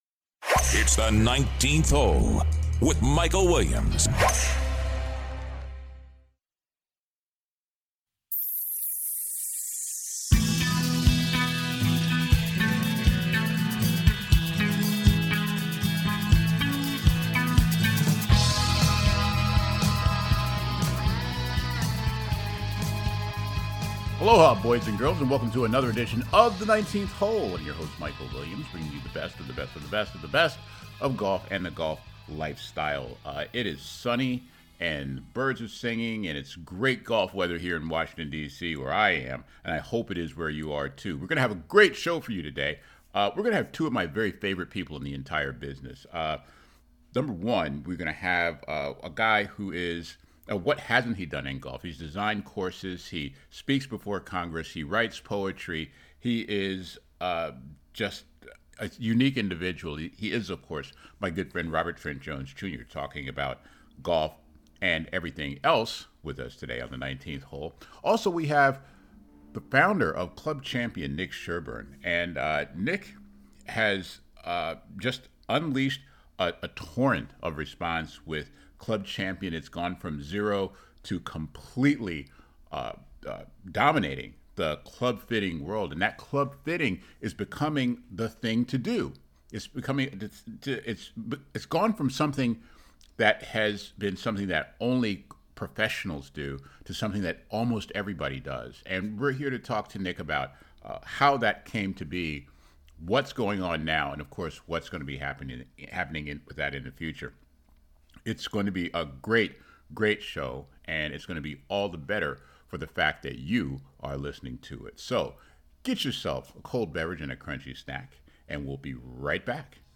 Hall of Fame golf course architect Robert Trent Jones II talks about Rory, the Masters and all things golf.